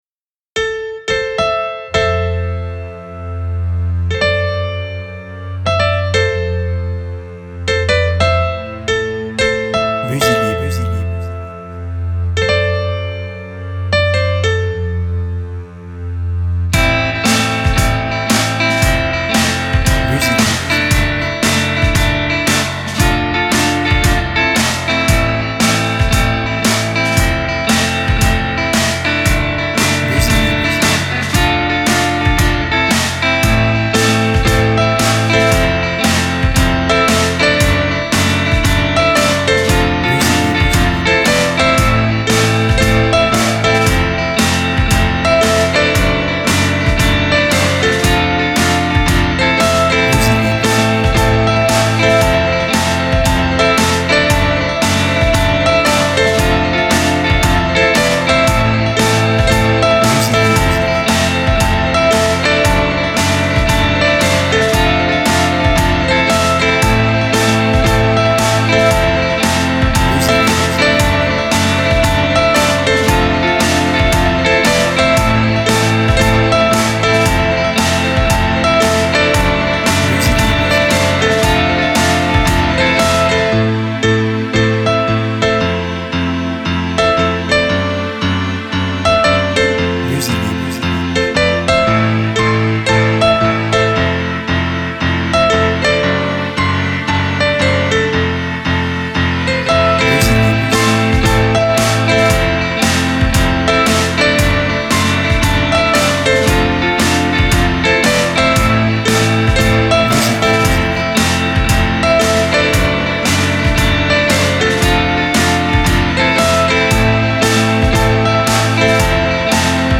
Ballade pop sur fond de pianos.
BPM Moyen